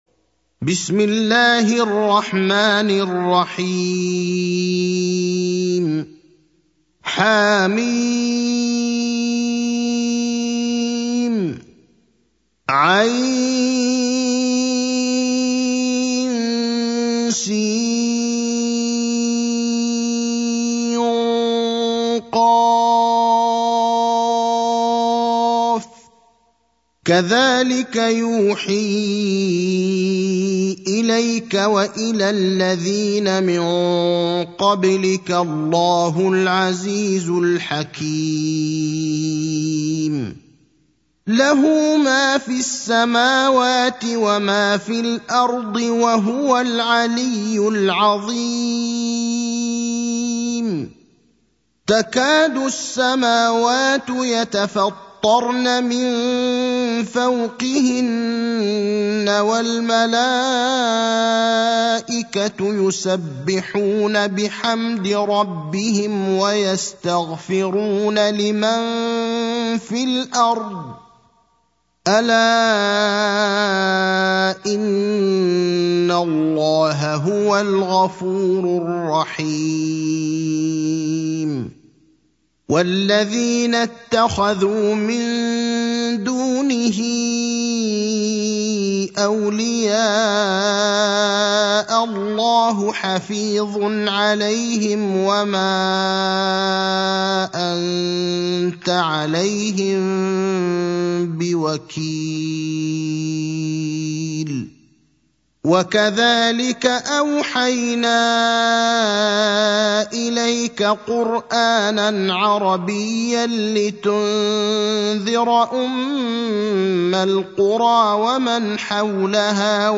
المكان: المسجد النبوي الشيخ: فضيلة الشيخ إبراهيم الأخضر فضيلة الشيخ إبراهيم الأخضر الشورى (42)